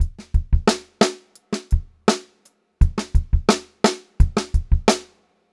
86STRBEAT6-R.wav